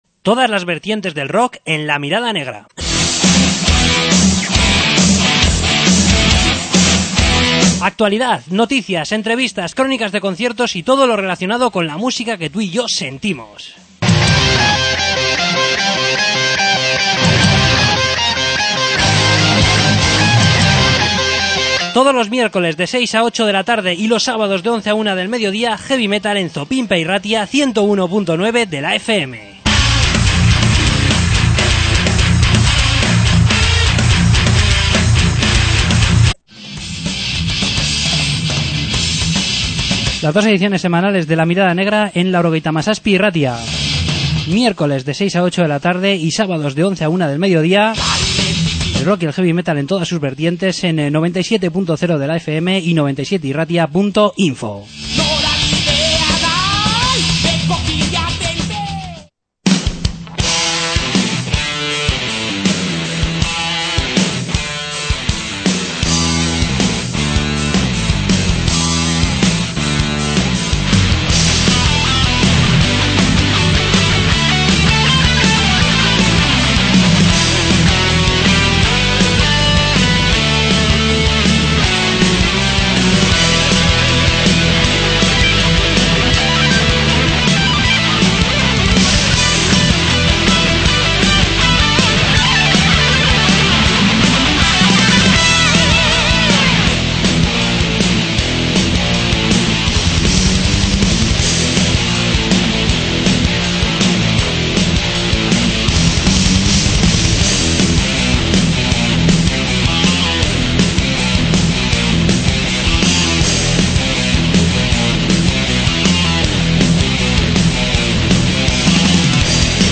Entrevista
Sección de noticias